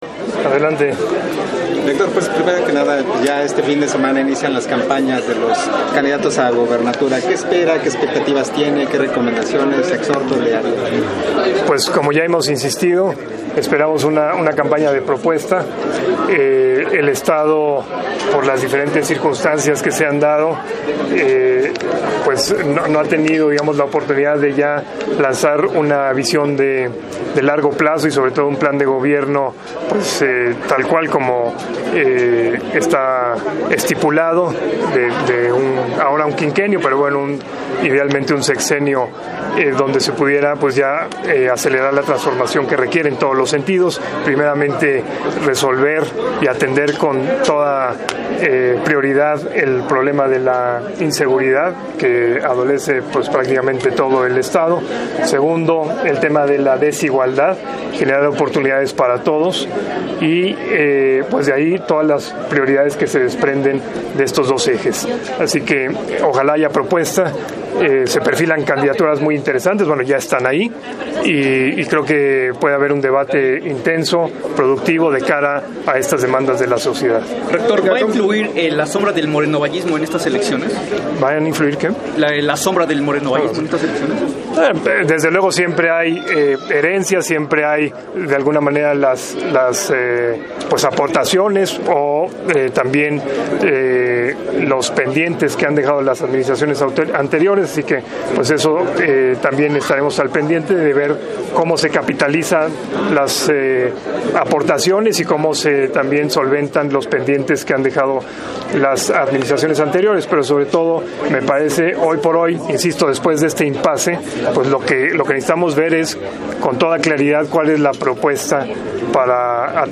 Entrevista-Rector-Medios.mp3